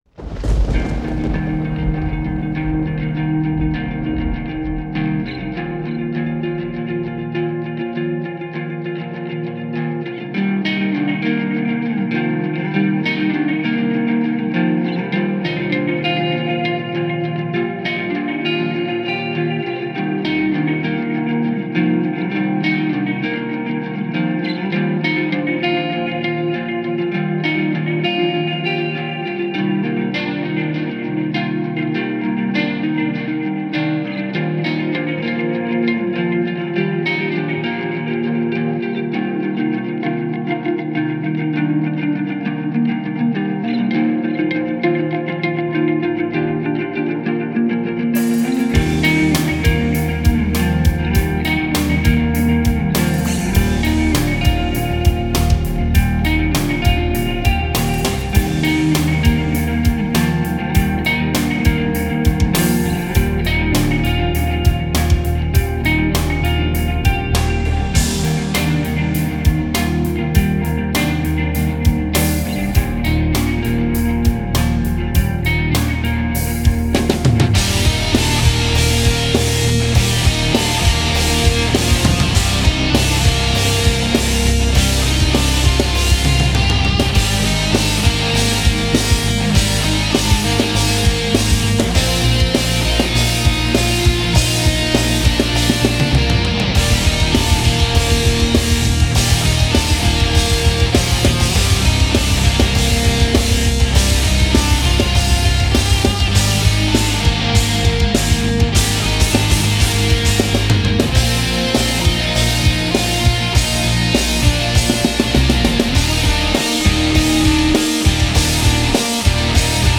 Alright, I turned down the kick during the distorted part of the song and turned up the cymbals slightly...
The guitars have some painful frequencies spiking in the upper mids. The snare during the distorted part sounds really muffled and the cymbals were already too loud before you turned them up.
Attached is my try at mastering. Those crash cymbals definitely need to be pulled back